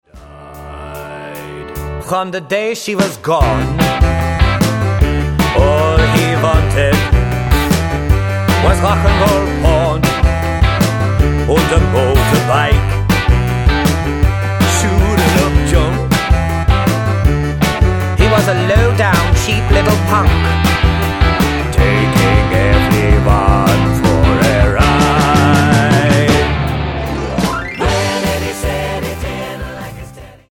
--> MP3 Demo abspielen...
Tonart:G Multifile (kein Sofortdownload.